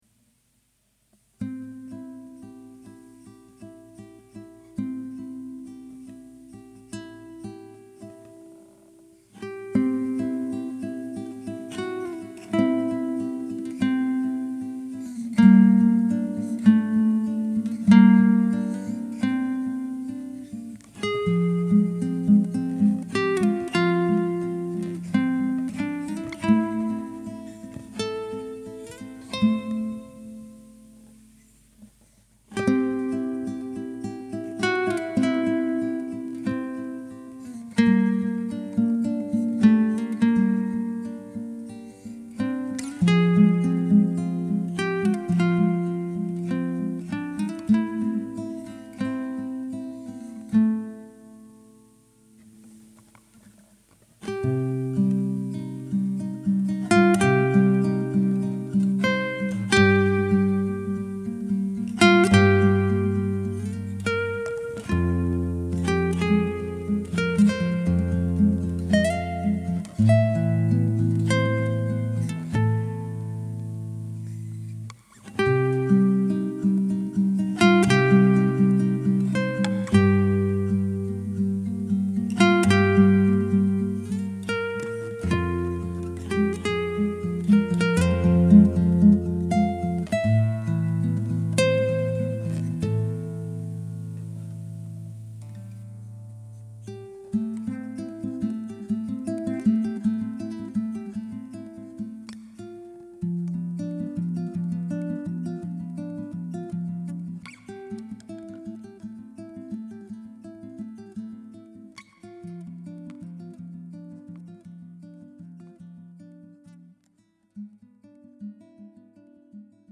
guitar cover
una versión a guitarra